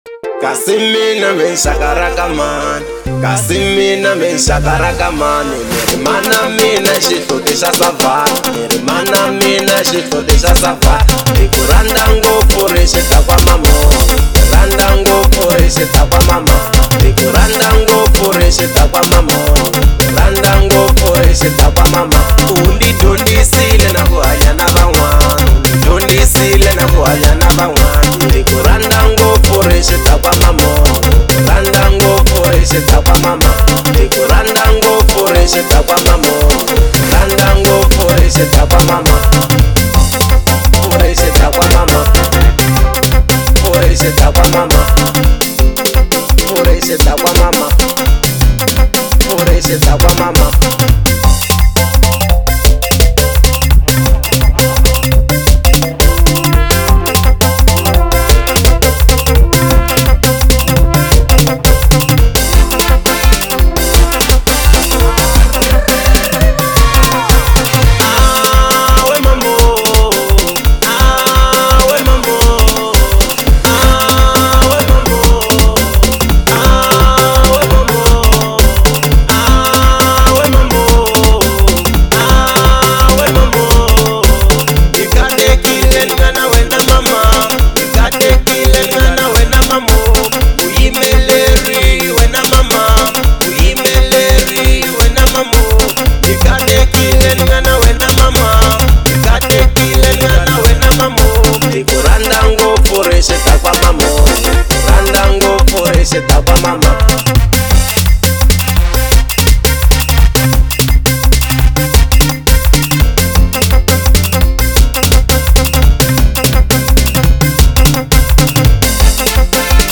03:25 Genre : Xitsonga Size